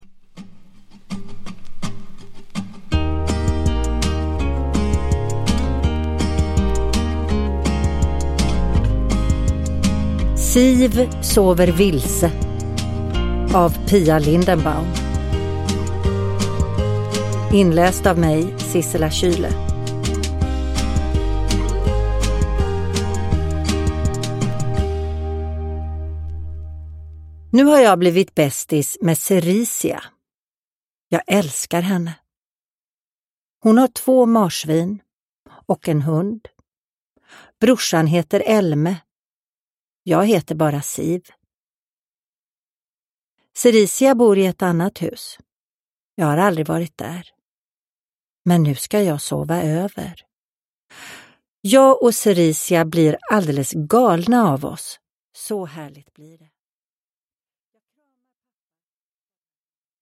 Siv sover vilse – Ljudbok – Laddas ner
Uppläsare: Sissela Kyle